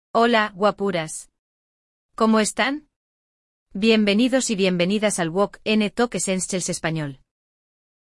Hoje, você vai ouvir a história de dois amigos venezuelanos, Felipe e Lucía, que decidiram passar cada semana das suas férias em um país diferente.
Sim! Este episódio, por exemplo, traz expressões típicas da Venezuela e variações na pronúncia.